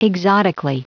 Prononciation du mot exotically en anglais (fichier audio)
Prononciation du mot : exotically